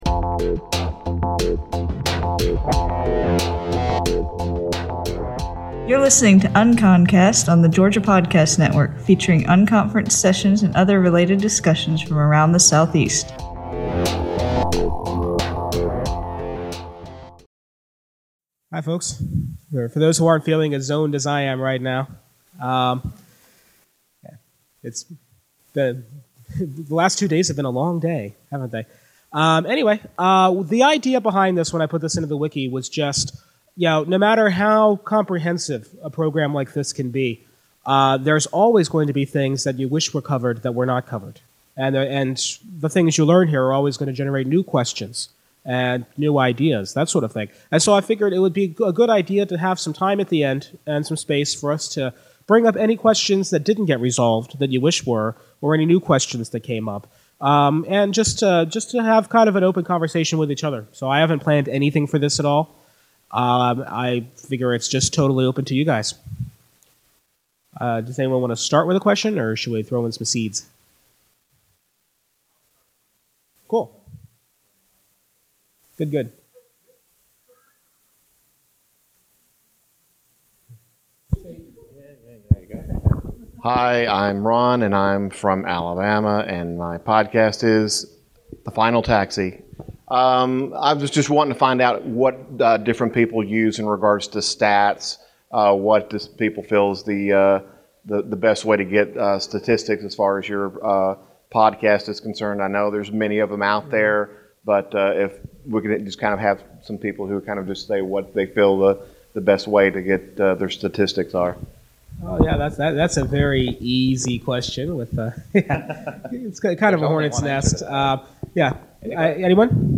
PodCamp Atlanta - Open Q&A | Georgia Podcast Network